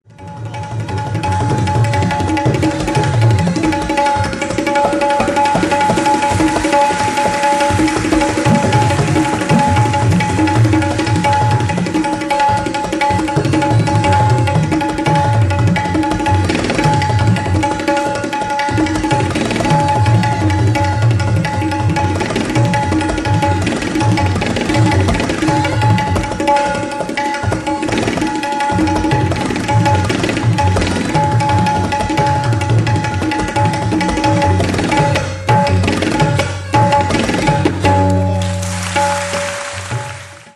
Табла
Он состоит из двух полых барабанов, снаружи обтянутых кожей, которая закрепляется при помощи кожаных ремешков, в свою очередь, стянутых кожаными креплениями.
В руках искусного мастера табла способна воспроизвести любой ритмический рисунок с четкими временными циклами – тал.
tabla.mp3